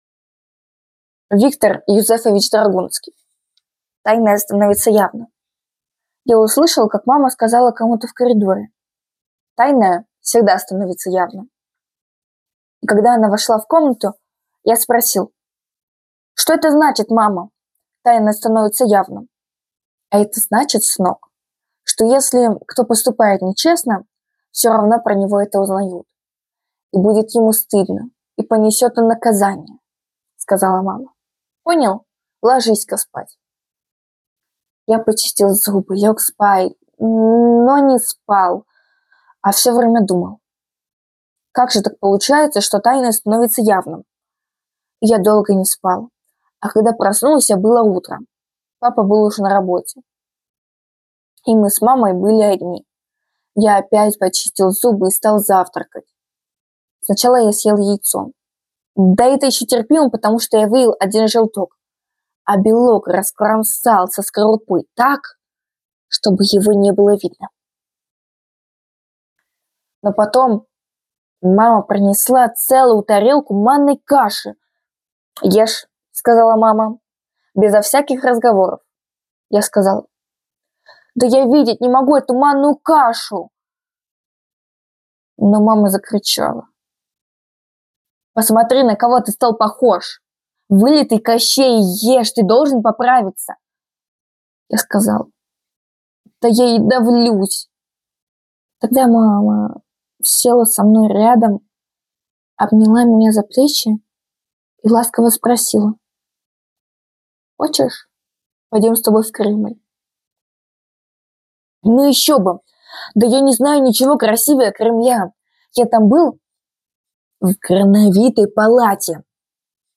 Поэтому подготовили для вас аудиоверсию рассказа «Тайное становится явным»!